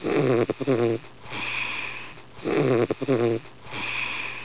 womansn.mp3